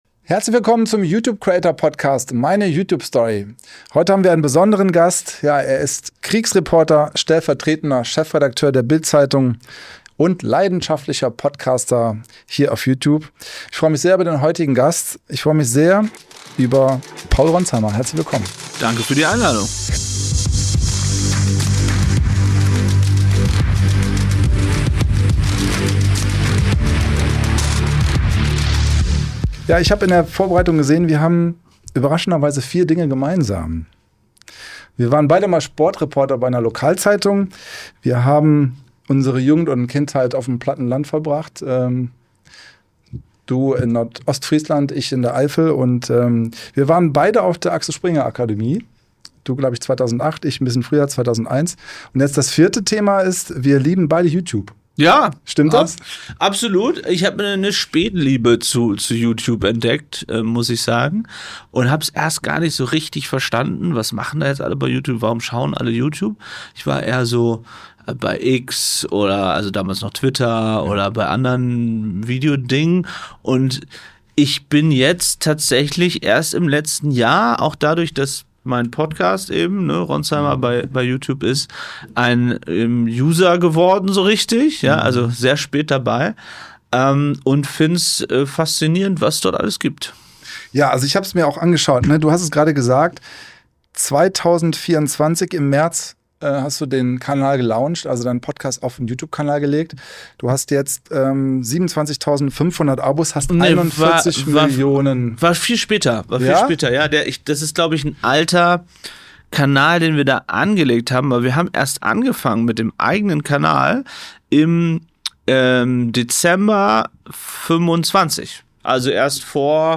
Zum Zeitpunkt des Gesprächs ahnte Paul Ronzheimer noch nichts von seinem Gewinn und zeigte sich im Nachhinein umso begeisterter über die Auszeichnung.